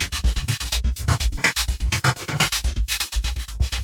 tx_perc_125_randonspectrum.wav